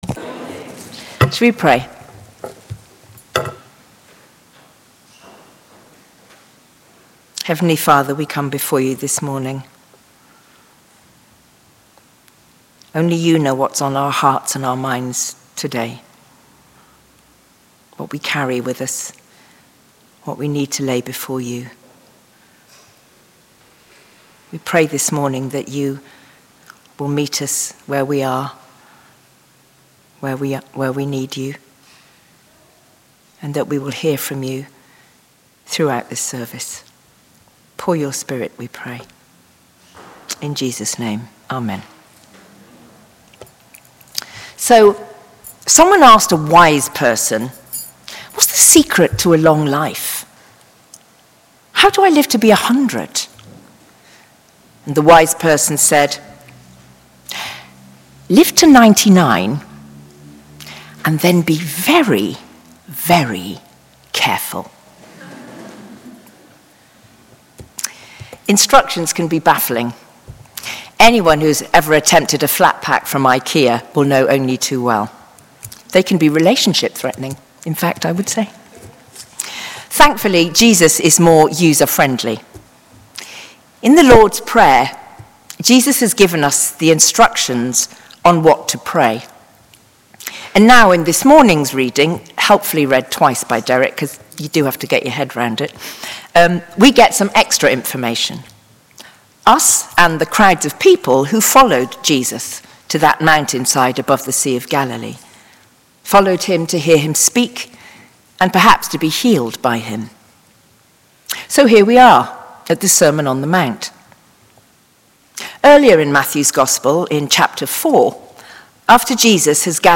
Listen to our 9.30am and 11.15am sermon here: